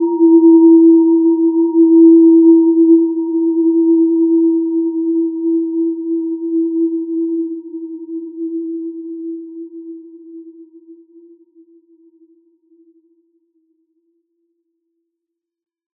Gentle-Metallic-3-E4-mf.wav